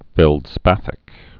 (fĕld-spăthĭk, fĕl-)